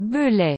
Belley (French pronunciation: [bəlɛ]
Fr-Belley-GT.wav.mp3